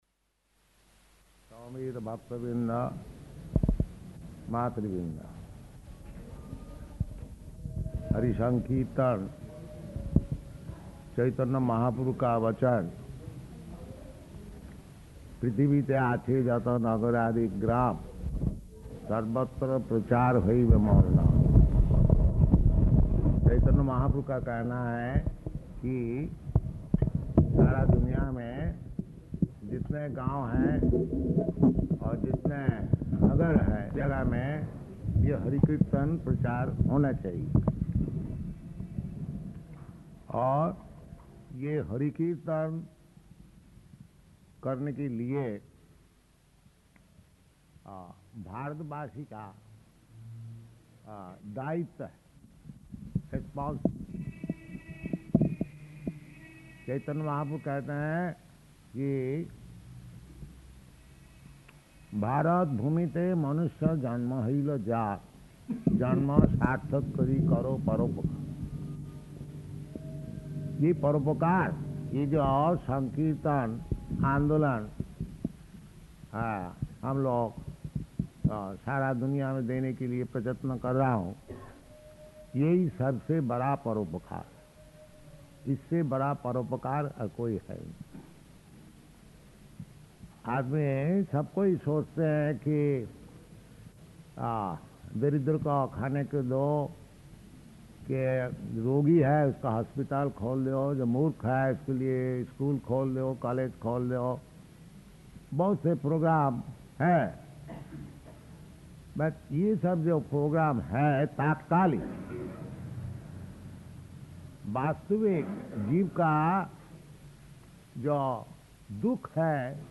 Lecture in Hindi
Lecture in Hindi --:-- --:-- Type: Other Dated: February 1st 1971 Location: Allahabad Audio file: 710201L4-ALLAHABAD.mp3 Prabhupāda: [Hindi] Paṇḍāl Lecture [Partially Recorded] Śrīmad-Bhāgavatam 6.3.11–12